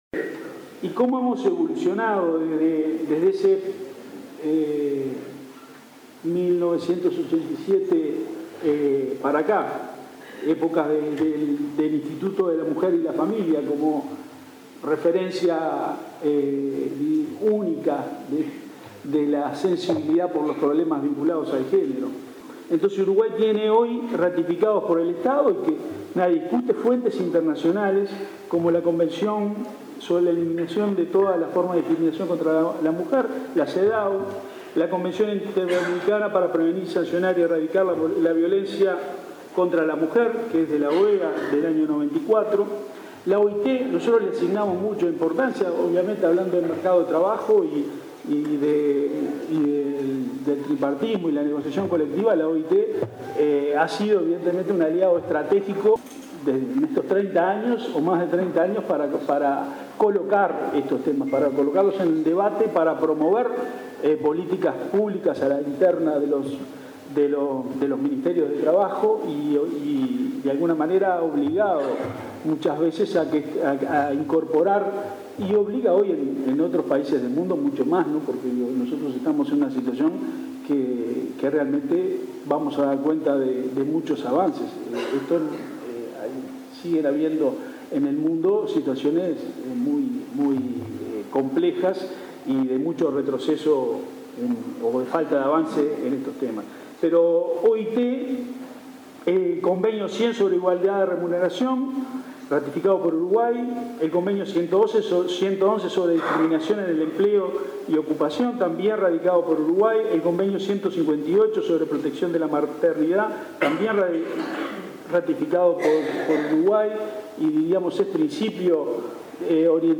El director nacional de Empleo, Eduardo Pereira, hizo un repaso de las políticas de género implementadas por el Gobierno, durante el seminario “Hacia un mercado de trabajo con igualdad de género", organizado por la Casa de la Mujer en su 30.º aniversario. Enumeró la normativa nacional que condena la discriminación contra la mujer, la que penaliza el despido de la trabajadora agraria y la que condena el acoso sexual laboral.